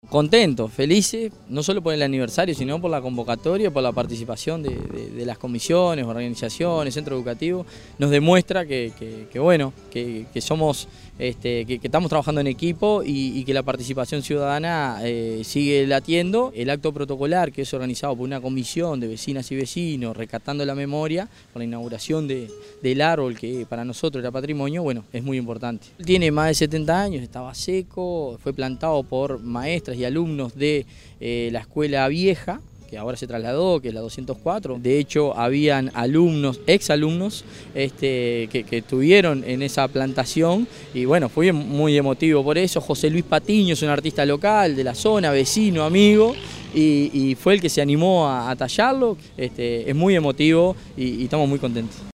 Con un buen marco de público, se realizó el acto en conmemoración del 151° aniversario del proceso fundacional de la ciudad de Progreso en el Club de Leones.
alcalde_del_municipio_de_progreso_claudio_duarte.mp3